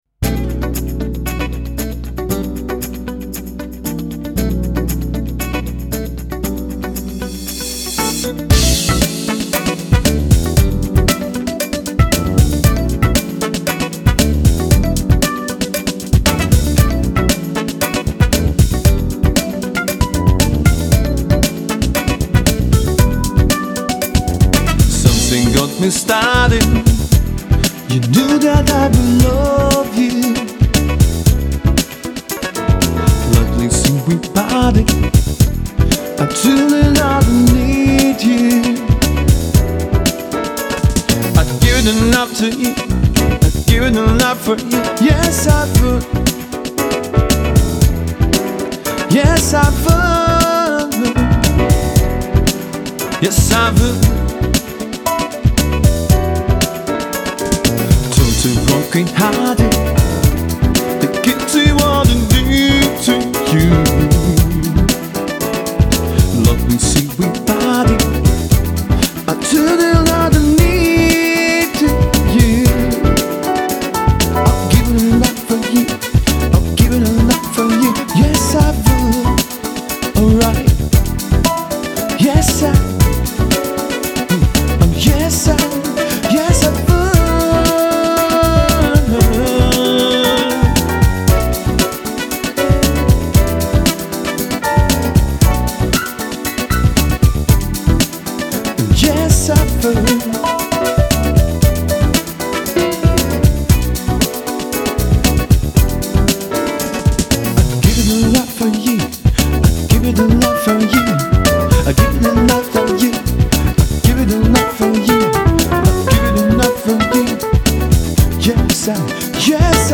erstklassiger Pianist für Ihre Veranstaltung